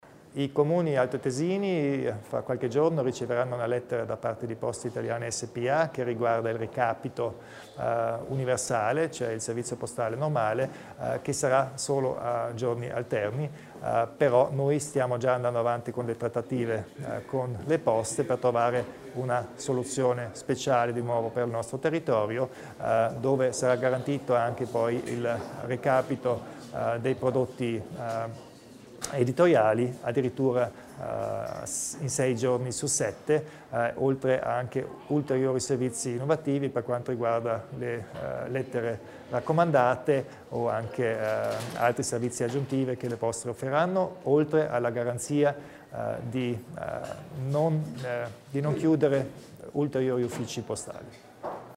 Il Presidente Kompatscher illustra le novità per le Poste in Alto Adige